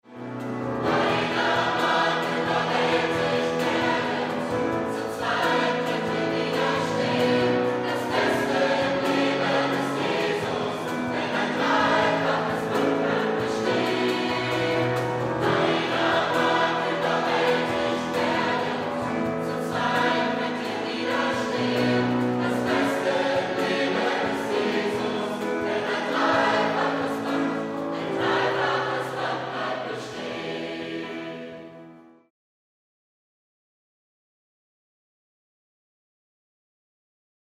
Notation: SATB + Backings
Tonart: E, G, F#
Taktart: 4/4
Tempo: 120 bpm
Parts: Intro, 2 Verse, 2 Refrains, Bridge